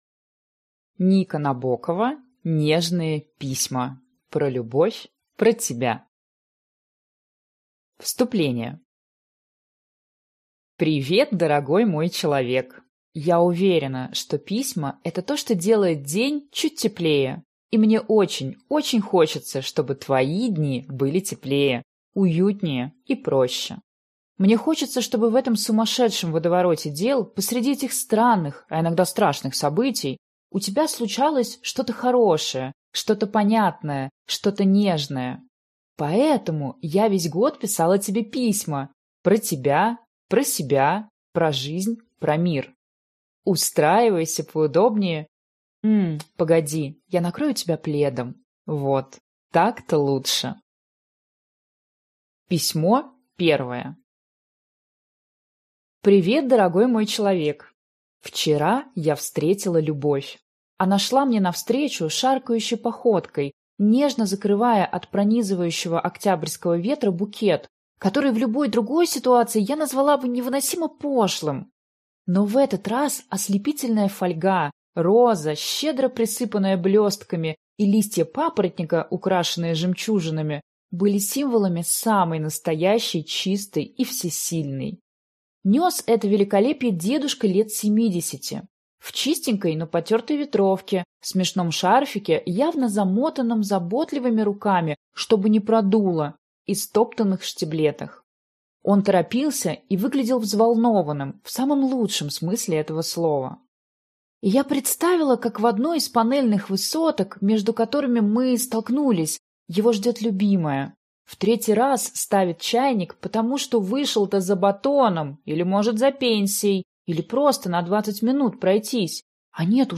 Аудиокнига Нежные письма. Про любовь, про тебя…